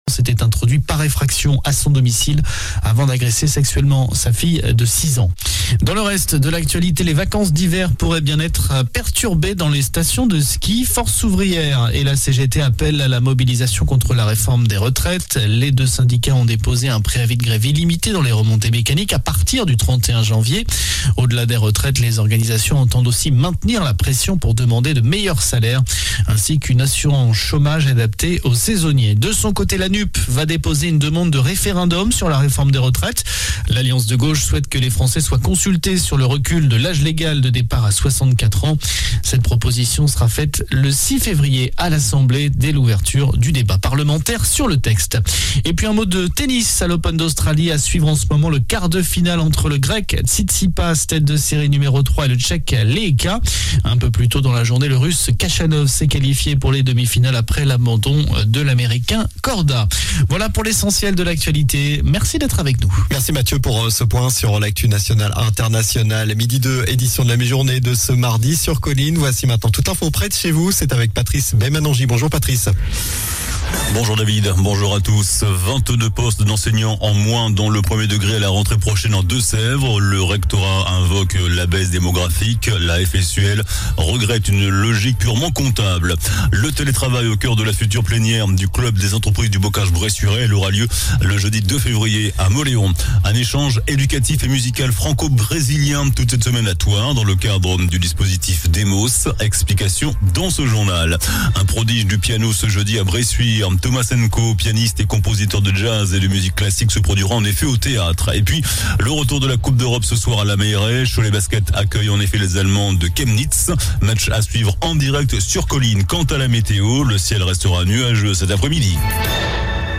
JOURNAL DU MARDI 24 JANVIER ( MIDI )